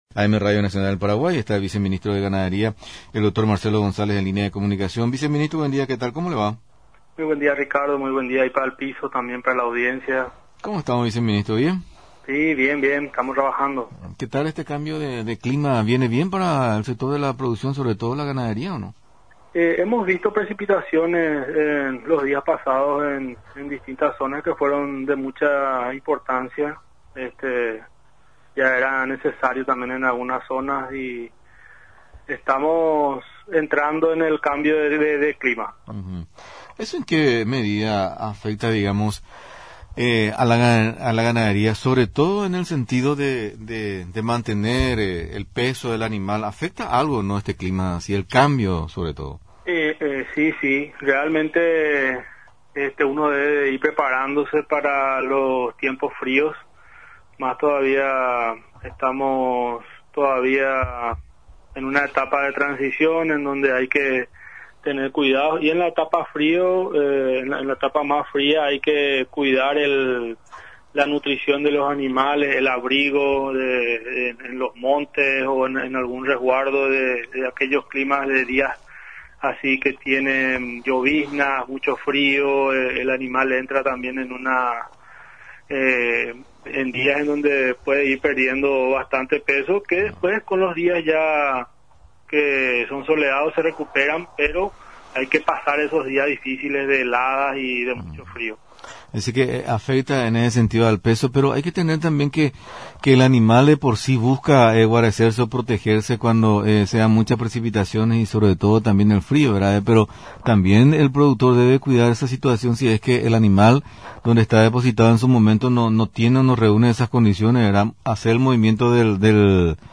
Vendrán técnicos turcos en el segundo semestre del año. Será importante acceder a ese gran mercado euroasiático, que actualmente compra sésamo y ka’a he’e, dijo el ViceMinistro de Ganadería, Marcelo González, en comunicación con Radio Nacional del Paraguay.